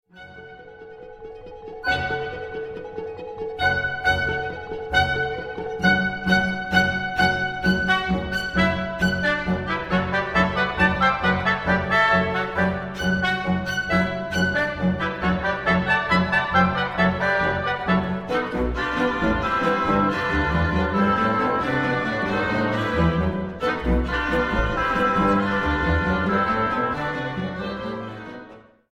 Originally a pianosolo, performed by Ebony Band